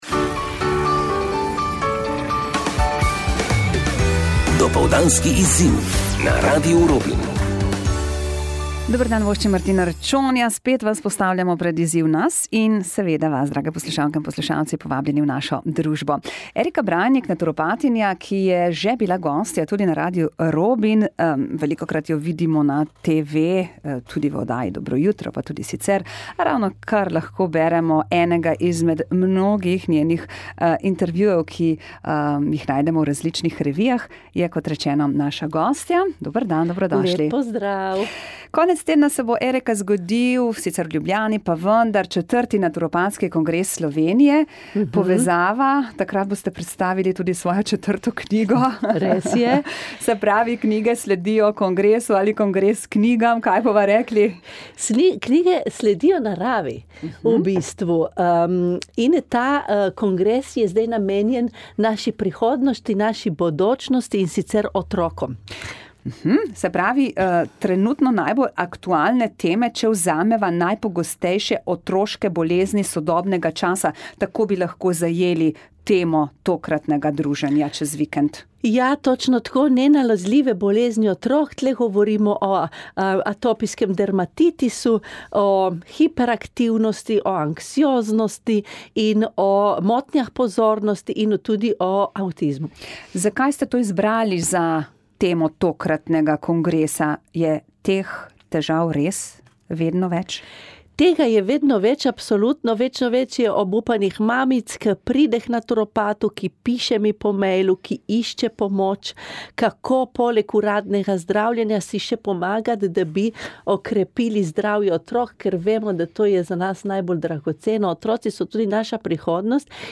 Najpogostejše otroške bolezni sodobnega časa, intervju na Radio Robin